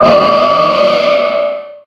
adds gen 7 icons & cries